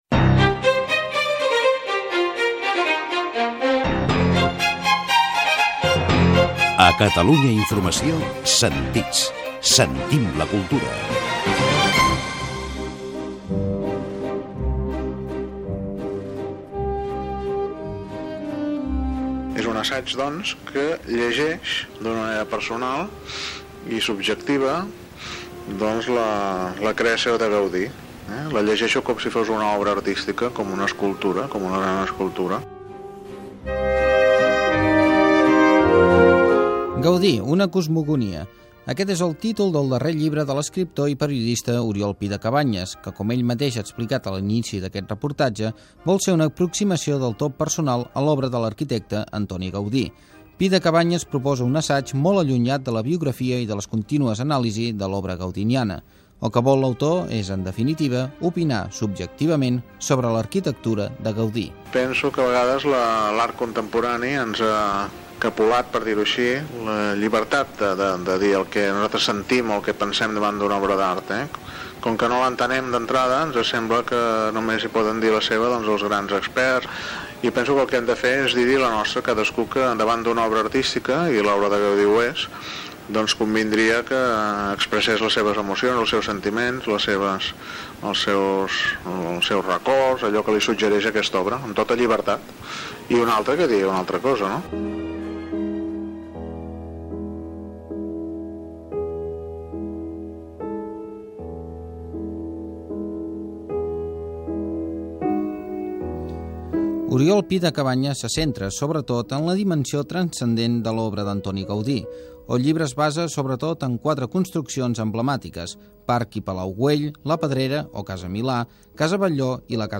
Careta del programa, reportatge sobre el llibre "Gaudí, una cosmogonia", d'Oriol Pi de Cabanyes.